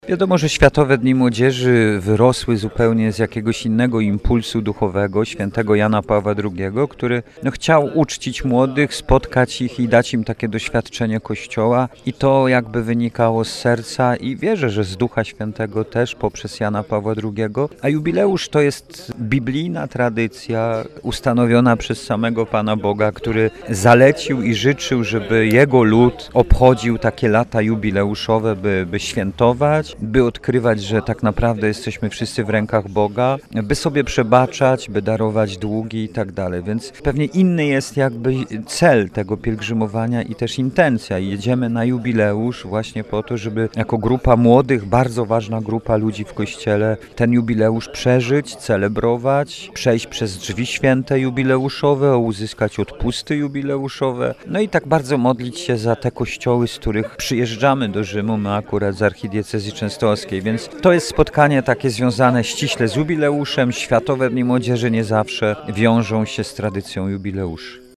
-Jubileusz to biblijna tradycja ustanowiona przez Boga – powiedział biskup pomocniczy archidiecezji częstochowskiej: